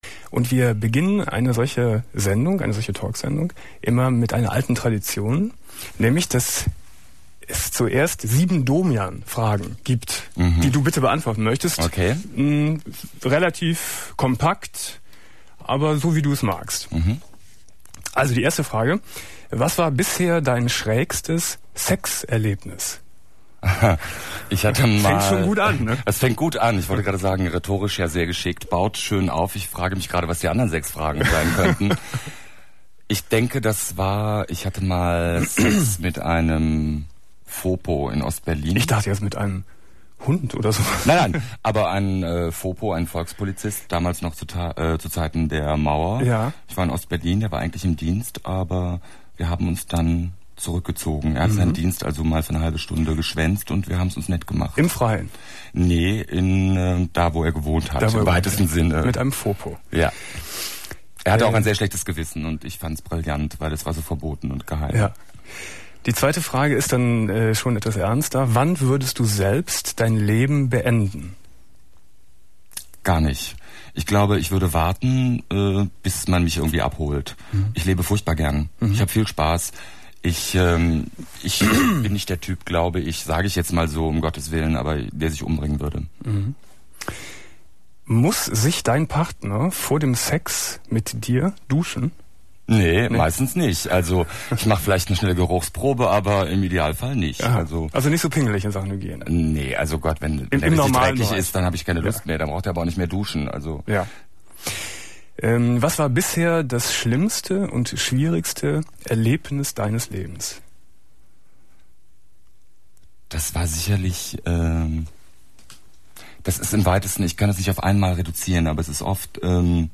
11.09.1999 Domian Studiogast: Georg Uecker ~ Domian Talkradio - Das Archiv Podcast